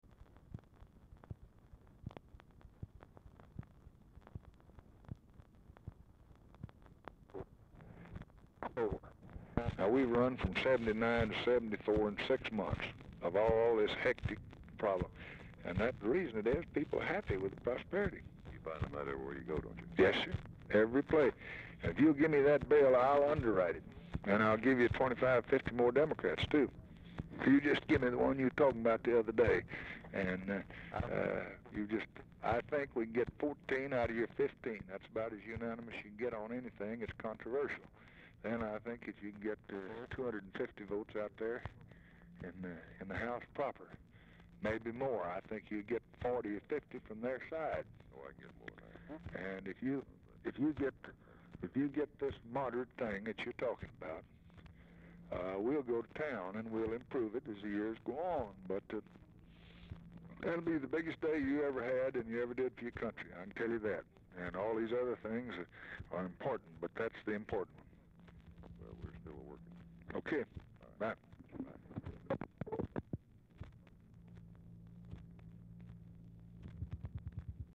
Telephone conversation
THIS RECORDING CONTAINS ONLY CONVERSATION BETWEEN LBJ, WILBUR MILLS
Format Dictation belt
Location Of Speaker 1 Oval Office or unknown location